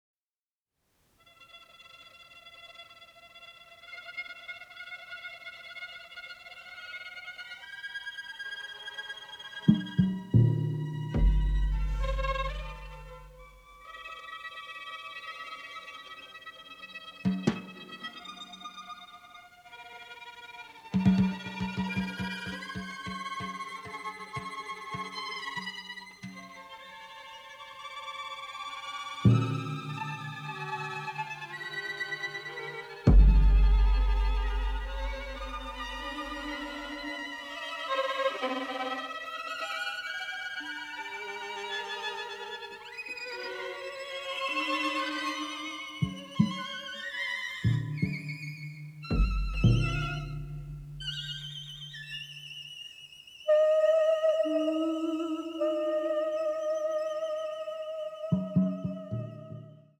avantgarde score
It had less music, but it was all in stereo.